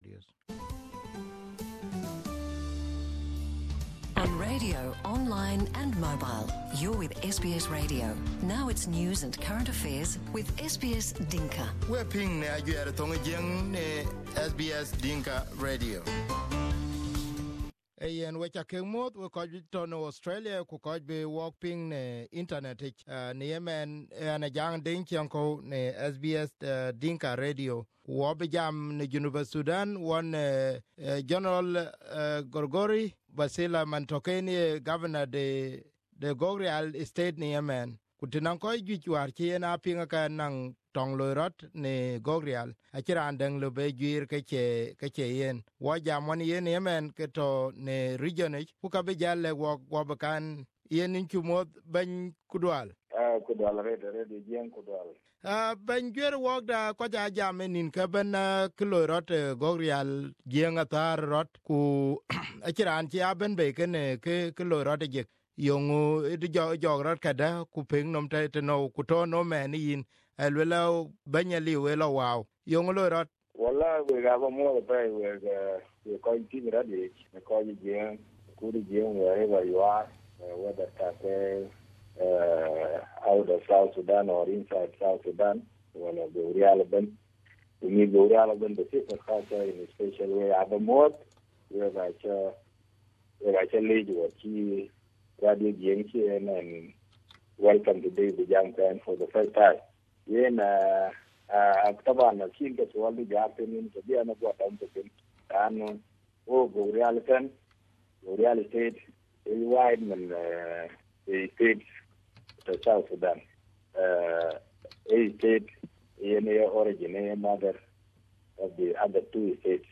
Here the first part of interview on SBS Dinka Radio with Governor Gregory Vasili.